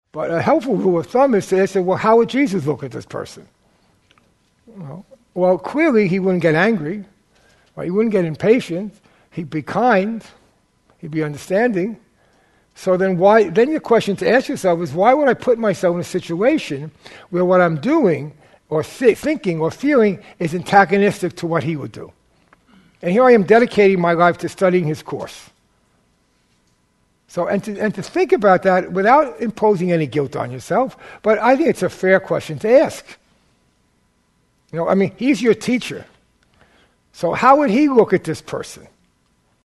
This 2013 five-day Academy class is built around two central themes, taken from two quotations.